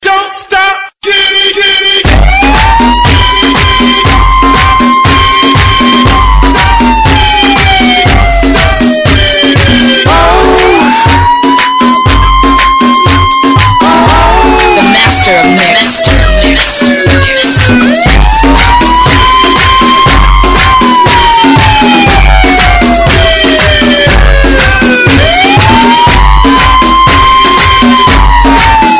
• Качество: 320, Stereo
громкие
мощные
remix
взрывные
Moombahton
Mashup
Сирена
Мощный звонок